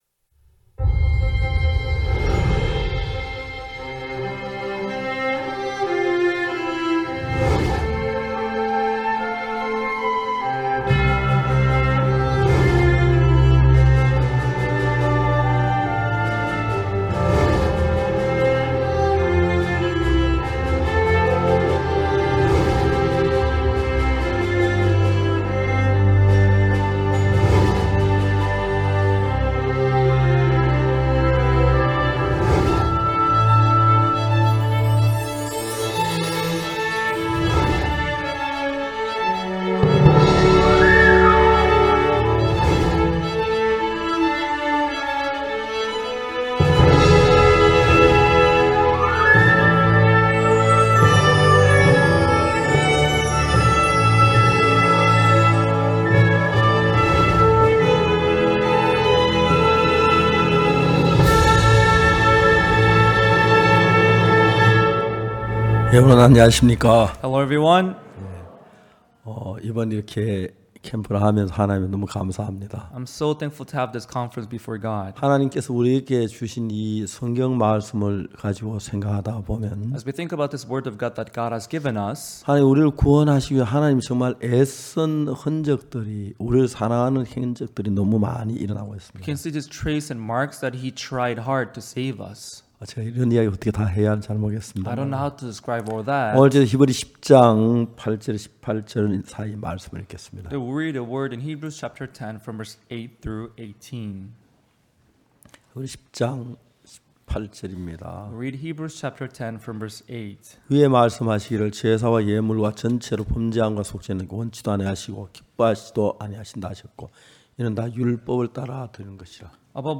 그 은혜로운 현장에서 울려퍼진 말씀들을 모았습니다.